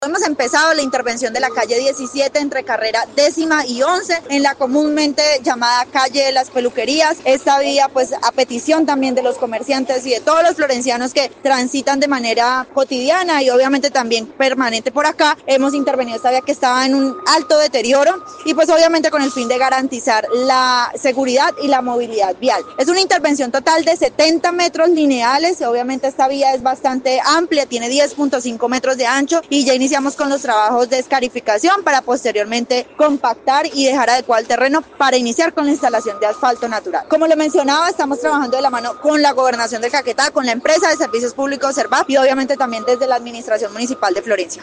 La secretaria de Obras, Anggy Méndez, explicó que, serán 70 metros lineales, donde se instalará asfalto natural, trabajos adelantados por la alcaldía, gobernación y SERVAF S.A ESP, por ello se cerrará la vía por los siguientes seis días.